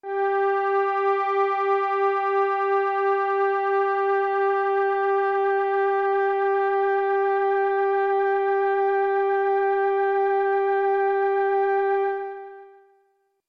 Fender Chroma Polaris Touch Pad " Fender Chroma Polaris Touch Pad F4 ( Touch Pad 0172)
标签： MIDI-速度-16 FSharp4 MIDI音符-67 挡泥板-色度北极星 合成器 单注意 多重采样
声道立体声